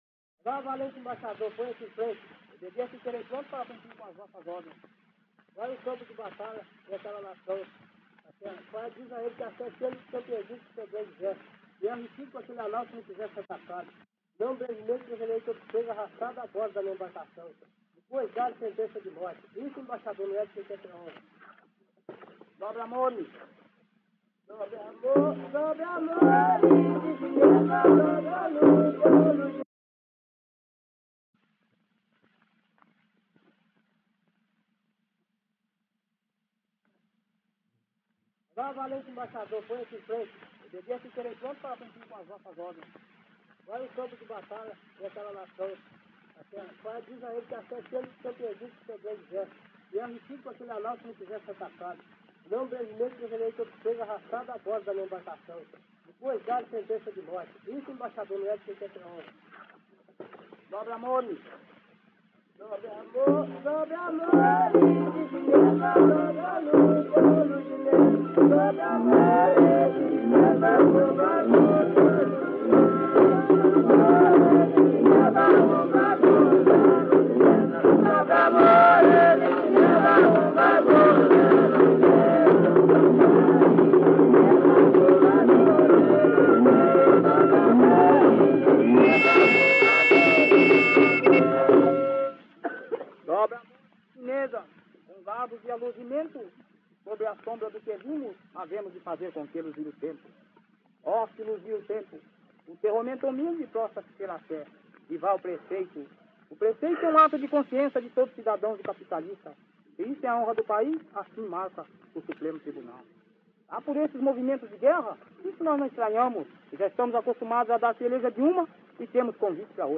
Fragmentos de embaixada de Congada (1)